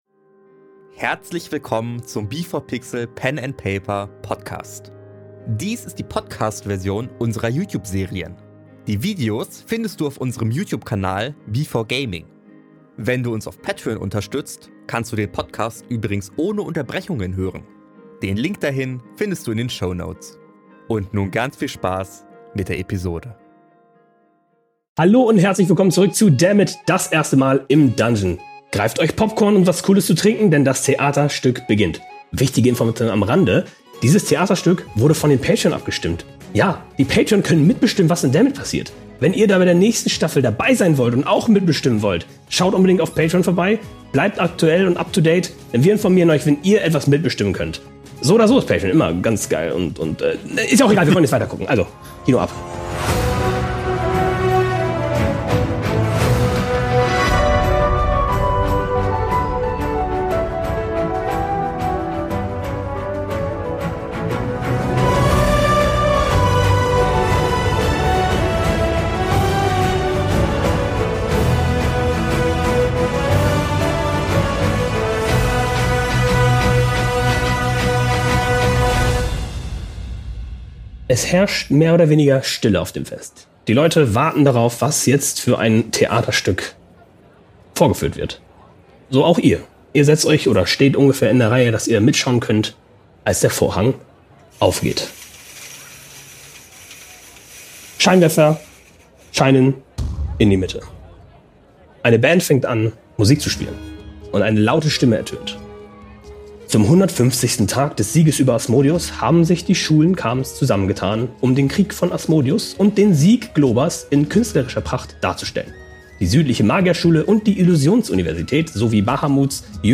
Dies hier ist die Podcast-Version mit Unterbrechungen.